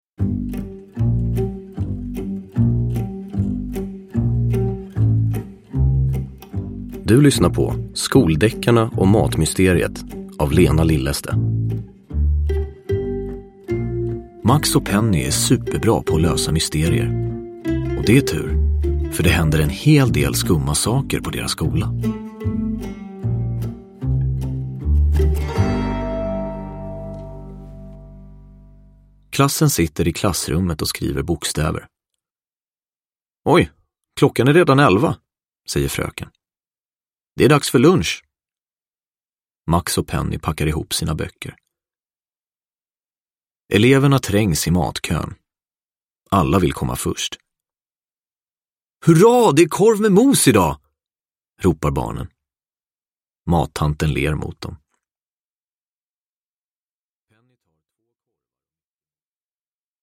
Mat-mysteriet – Ljudbok